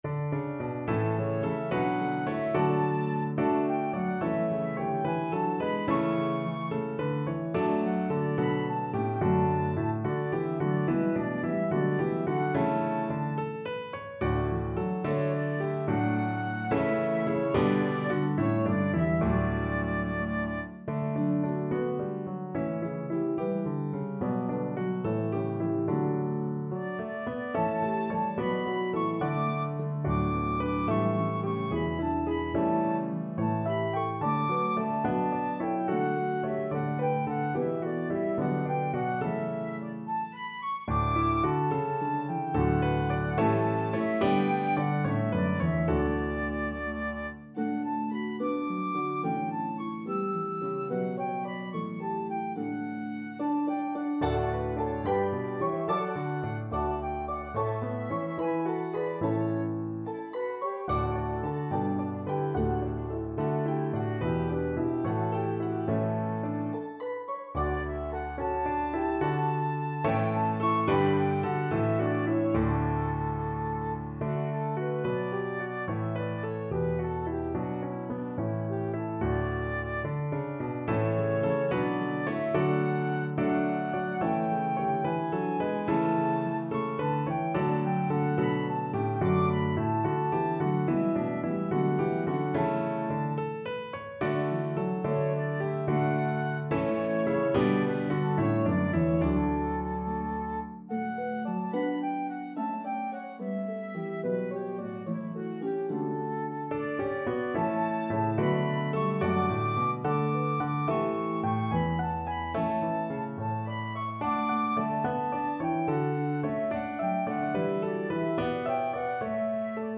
French Carol